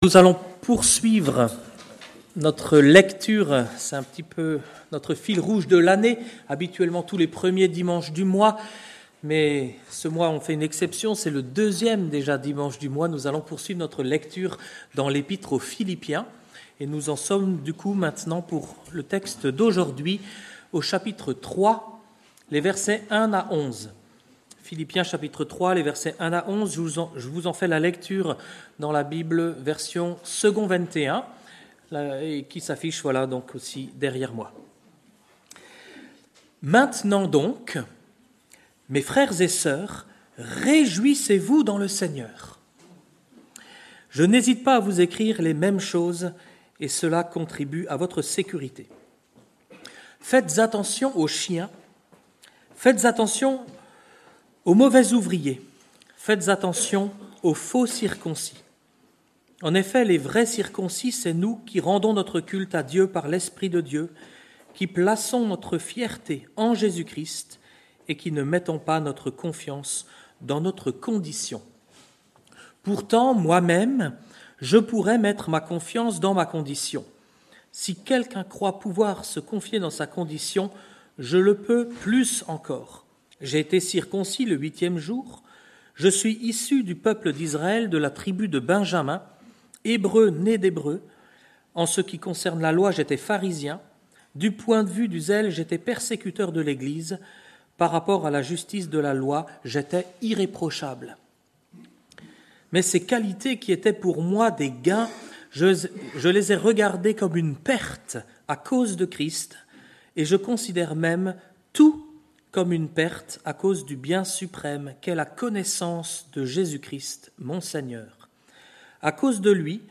Culte du dimanche 9 février 2025 – Église de La Bonne Nouvelle